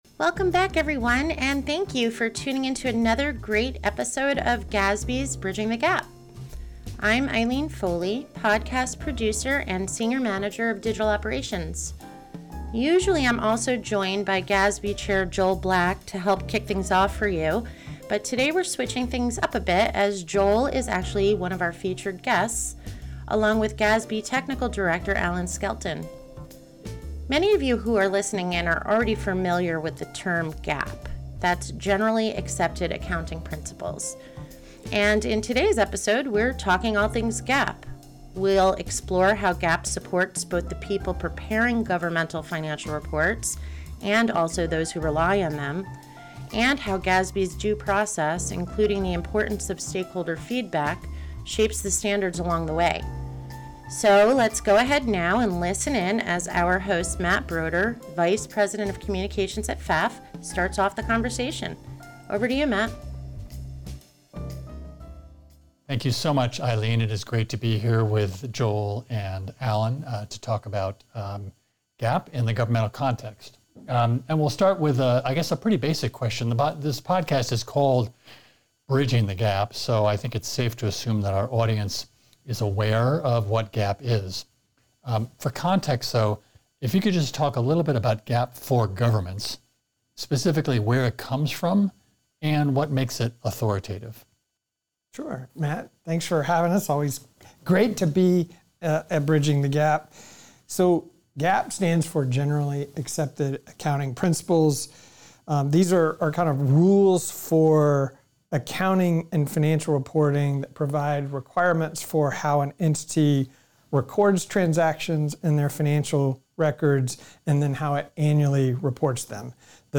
an insightful conversation